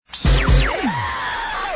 Section#2-Jingles, music logos
All tracks encoded in mp3 audio lo-fi quality.